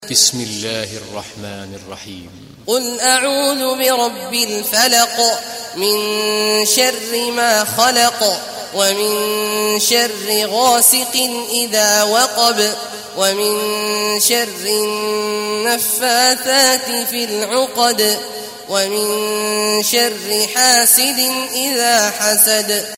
সূরা আল-ফালাক্ব mp3 ডাউনলোড Abdullah Awad Al Juhani (উপন্যাস Hafs)